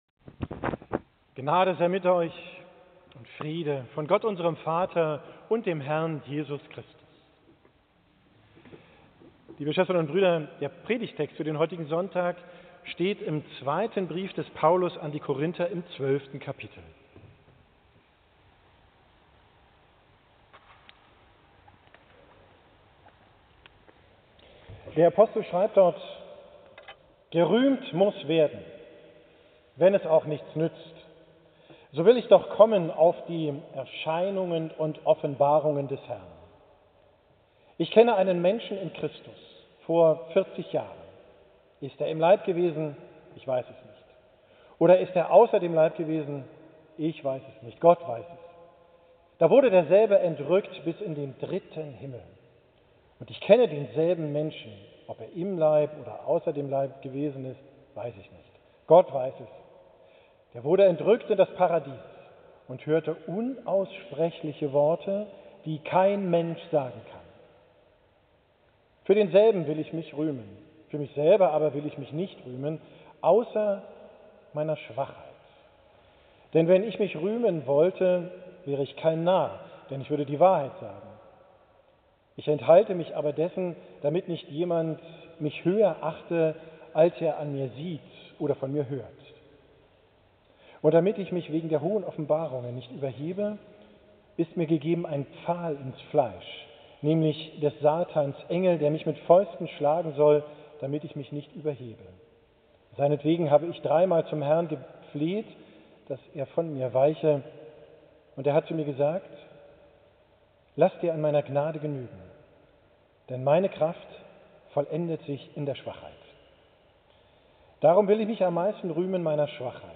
Predigt vom 5.